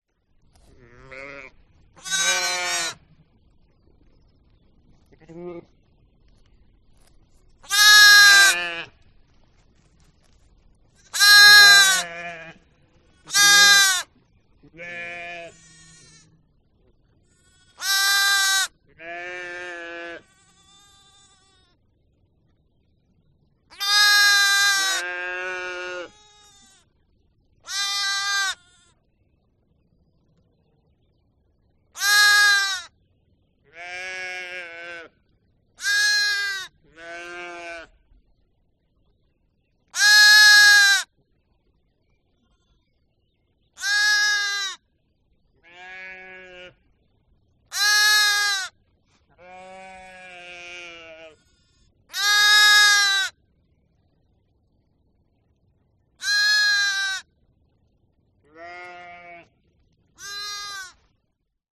Звук овцы с ягненком